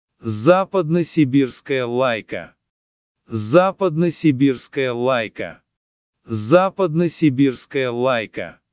Say it in Russian: